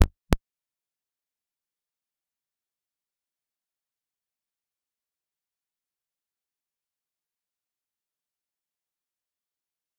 G_Kalimba-C0-f.wav